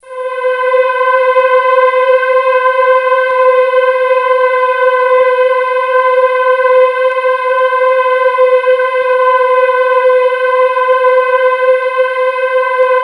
STRINGLOW -L.wav